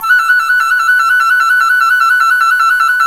Index of /90_sSampleCDs/Roland LCDP04 Orchestral Winds/FLT_C Flute FX/FLT_C Flt Trill